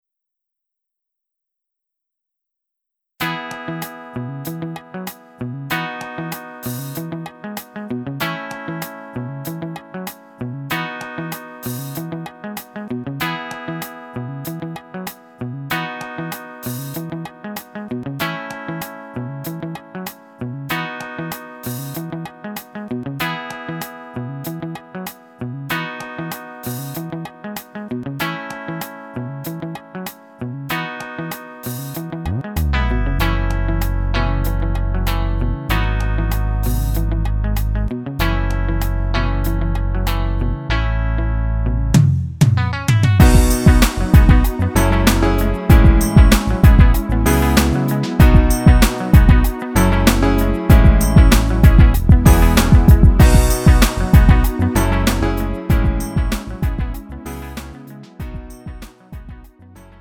음정 -1키
장르 pop 구분 Lite MR